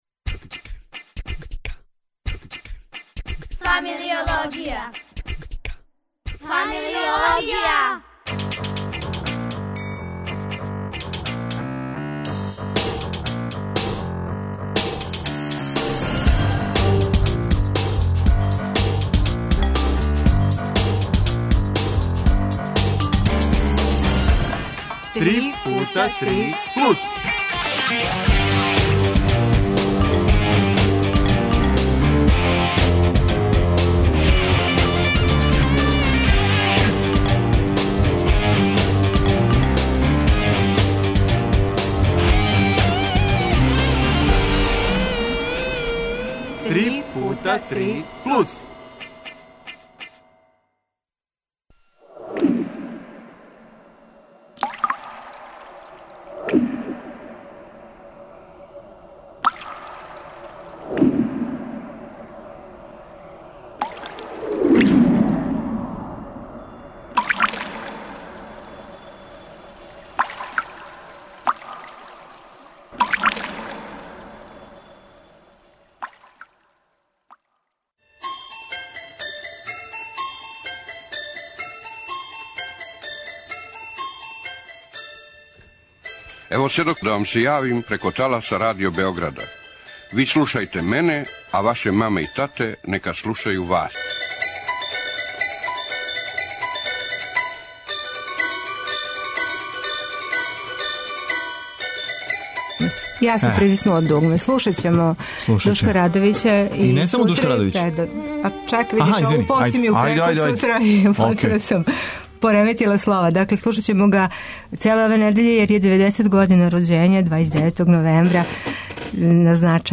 У нашој емисији разговор о младима и џезу.